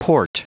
Prononciation du mot port en anglais (fichier audio)
Prononciation du mot : port